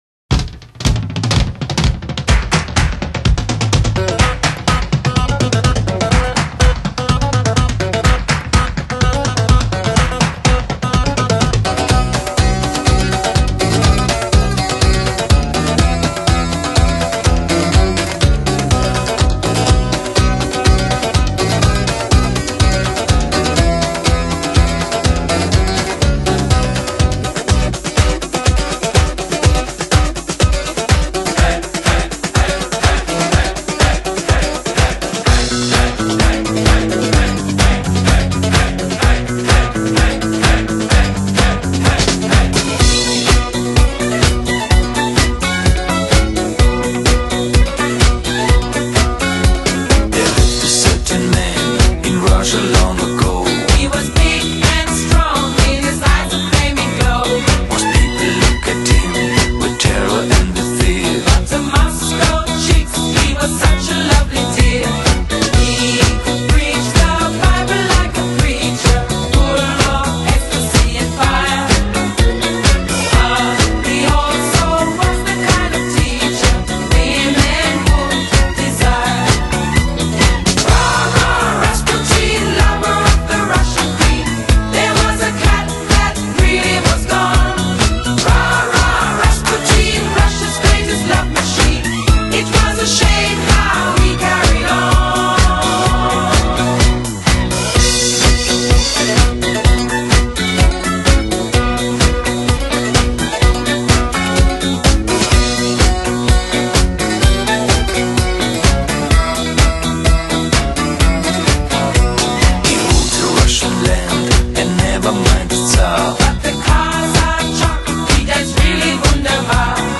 Disco, Pop